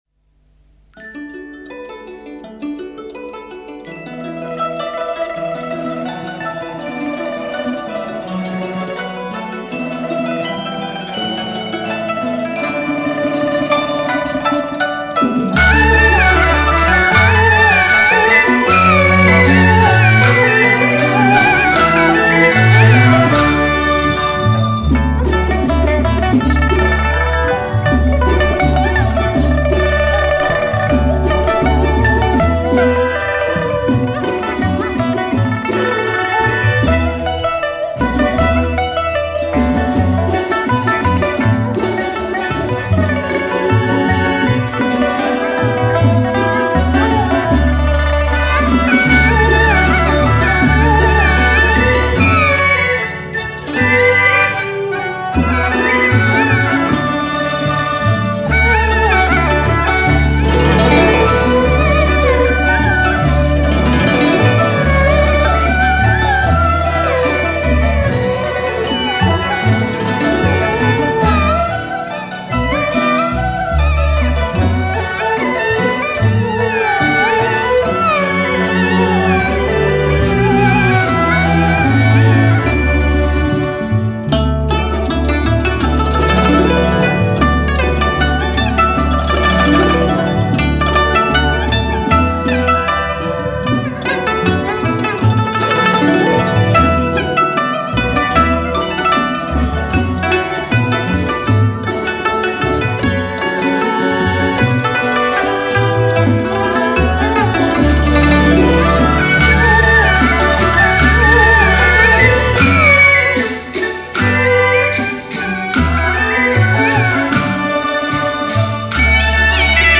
[17/5/2011]中国宫廷乐社演奏《放风筝》 激动社区，陪你一起慢慢变老！